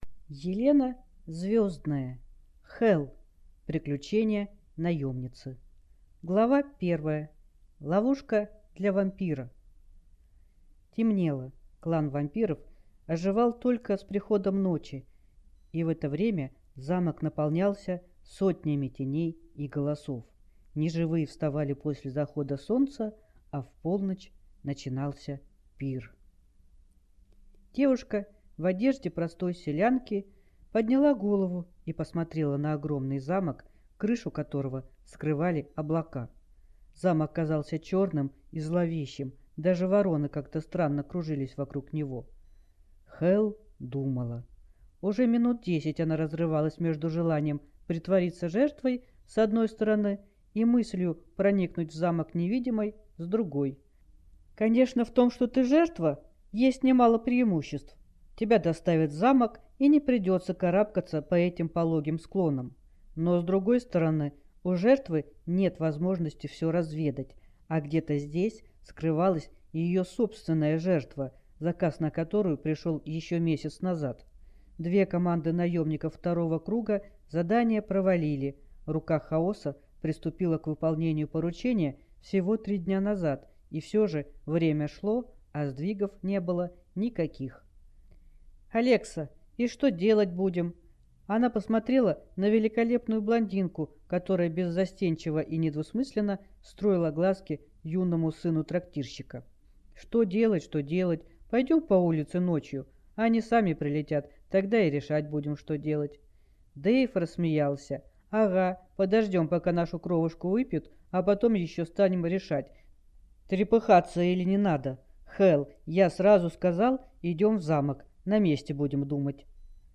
Аудиокнига Хелл.